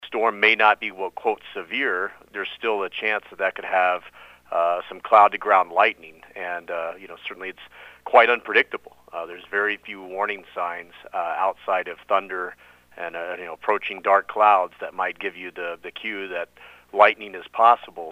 News Brief